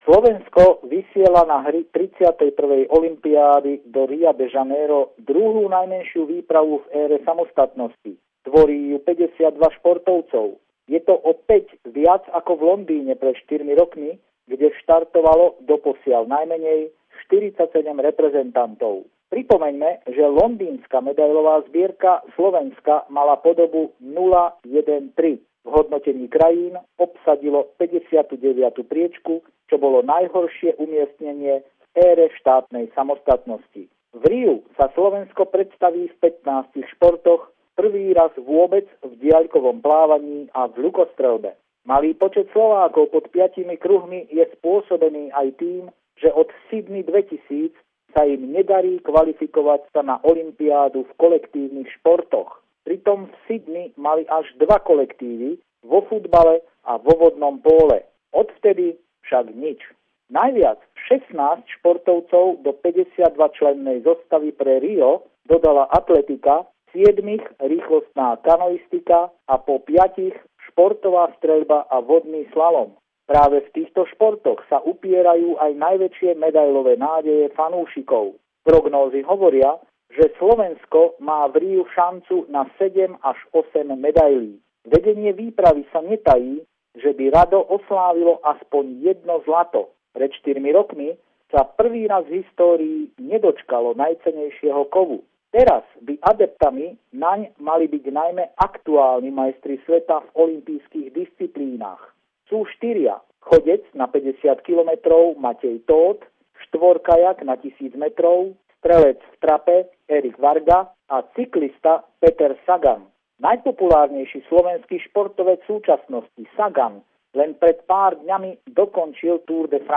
Pravidelný telefonát týždňa z Bratislavy od nášho kolegu